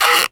R - Foley 166.wav